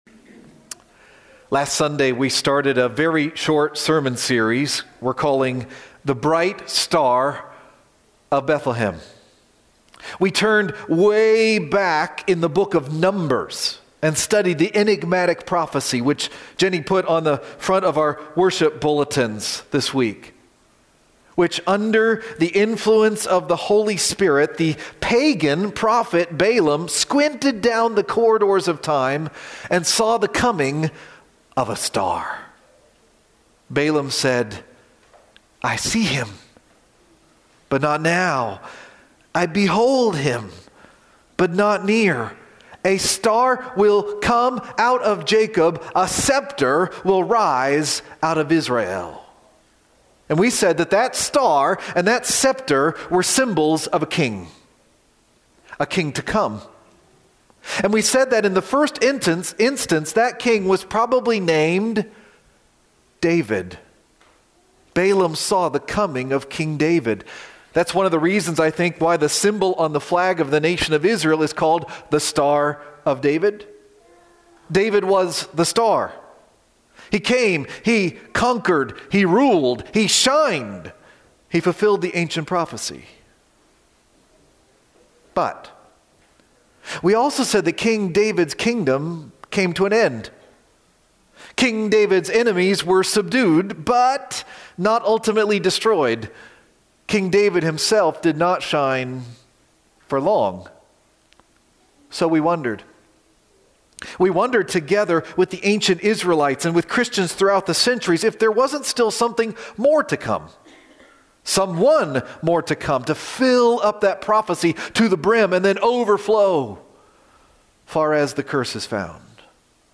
We Saw His Star - December 22, 2024 - Lanse Free Church :: Lanse, PA